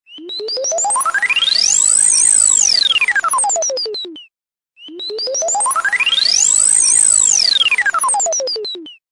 Звук космического корабля в космосе